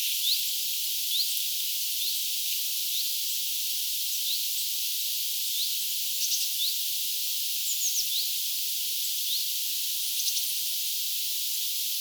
tämän päivän hyit-tiltalttihavainto
hyit-tiltaltti.mp3